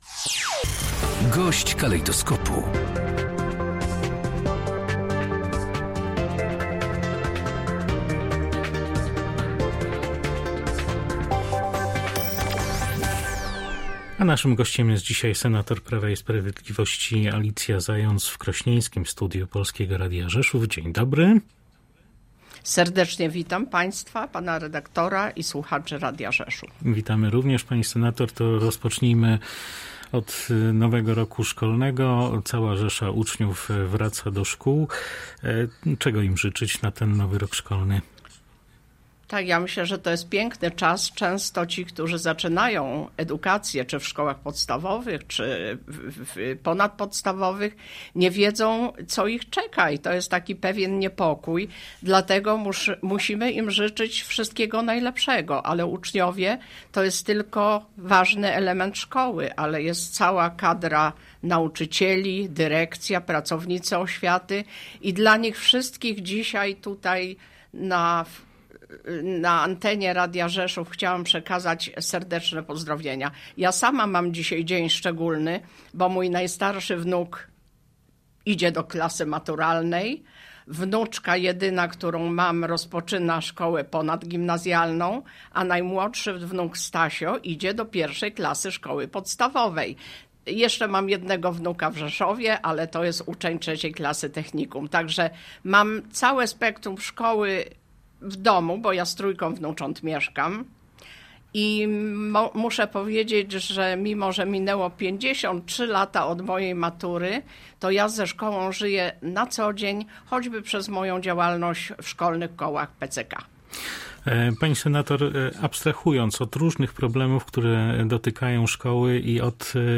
W pierwszym dniu nowego roku szkolnego rozmawialiśmy z naszym gościem o problemach polskiej edukacji.